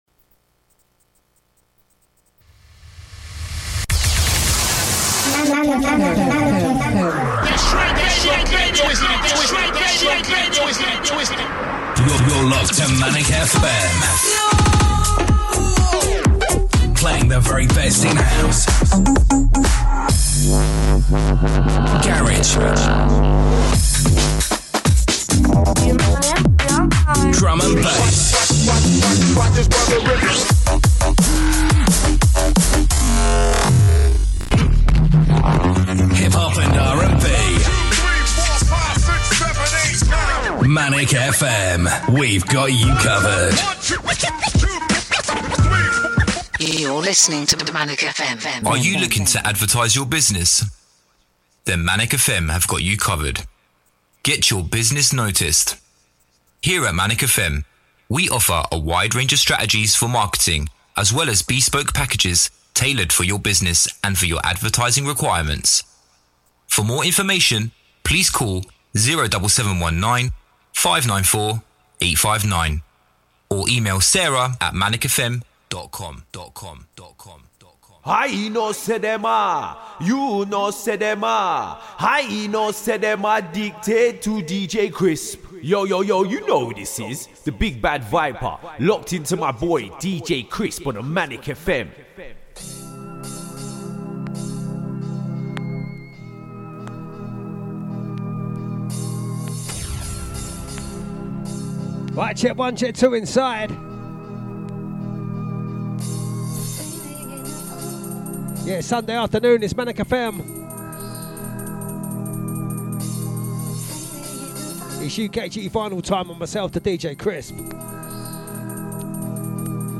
UKG VINYL